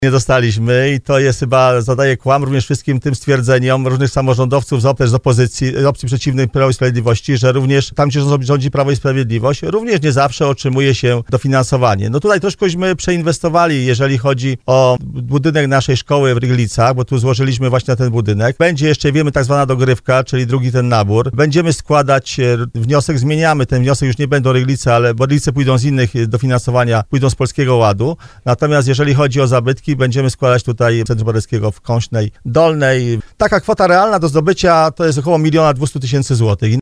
Pytaliśmy o to starostę, który był gościem audycji Słowo za Słowo.